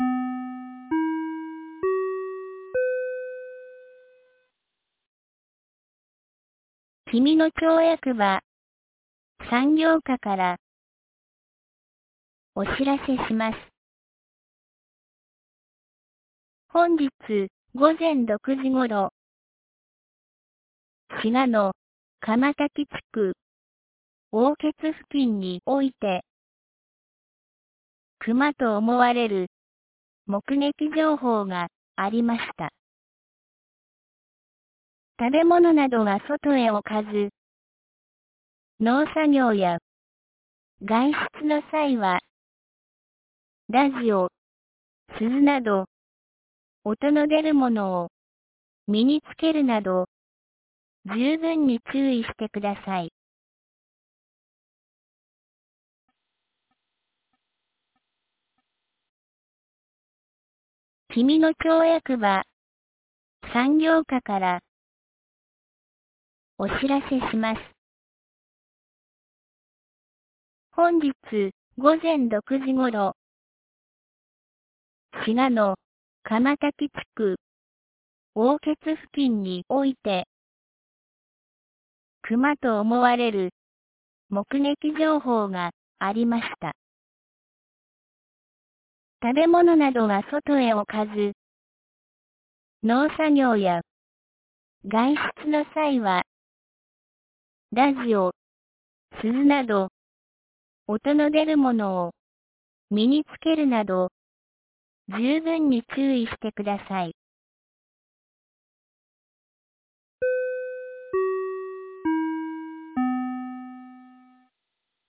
2022年08月01日 17時07分に、紀美野町より志賀野地区、東野上地区へ放送がありました。